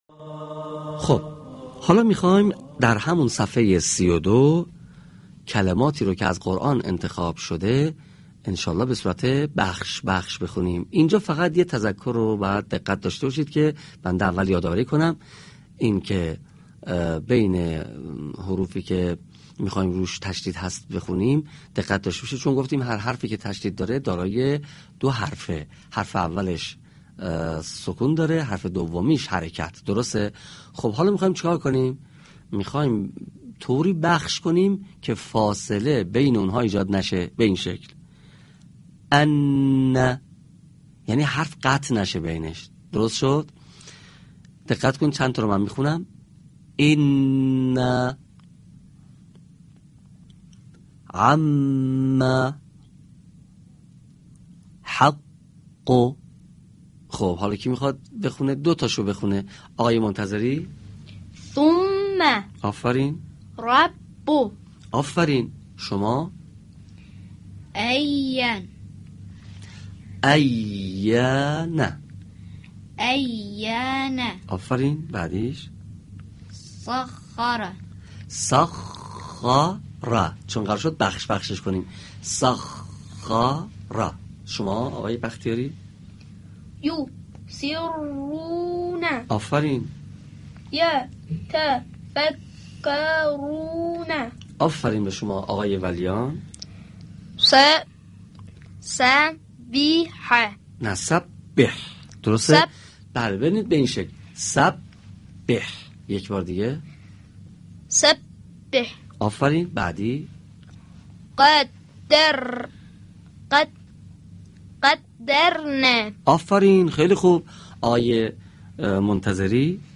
فایل صوتی/تمرین درس ششم_علامت تشدید